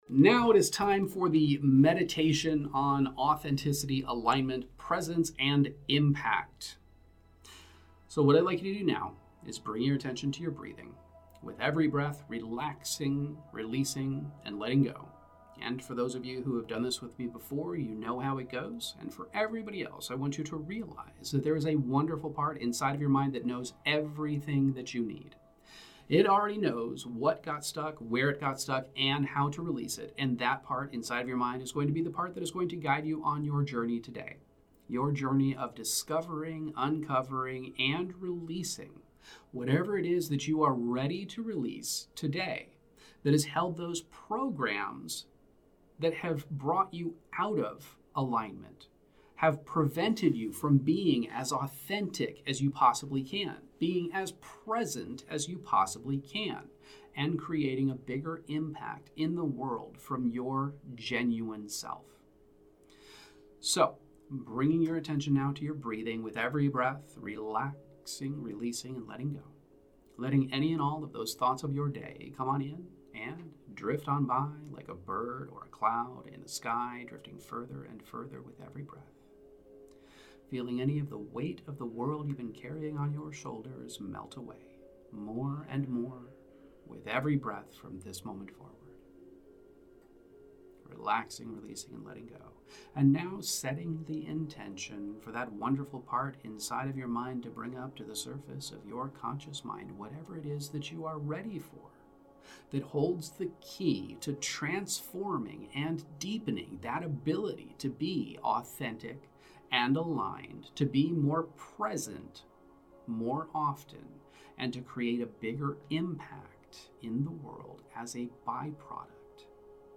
This guided hypnosis meditation guides you on a profound journey into the depths of your mind to uncover and transform the roots of fears, insecurities, and barriers that block your authenticity and alignment. You're able to identify and reinterpret memories or emotions that have contributed to a scarcity mindset or prevented genuine expression.
Emotional Optimization™ Meditations